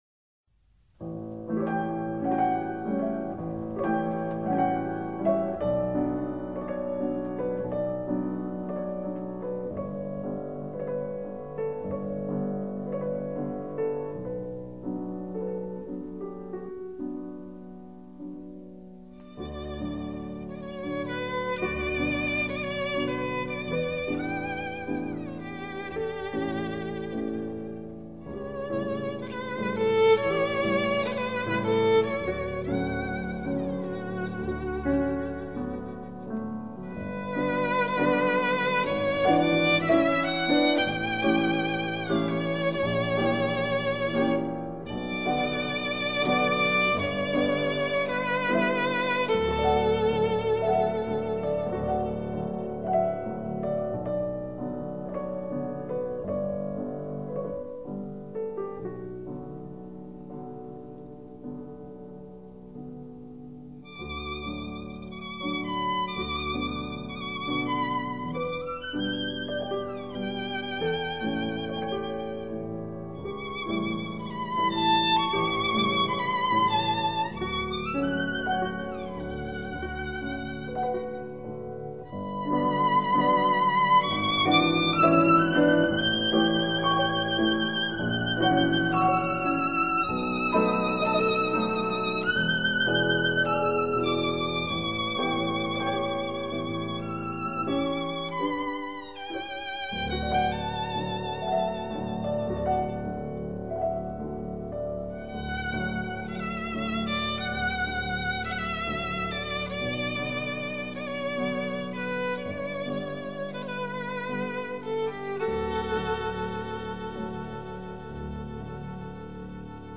古典美乐